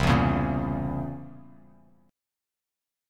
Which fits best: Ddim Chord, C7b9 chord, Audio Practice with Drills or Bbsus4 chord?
C7b9 chord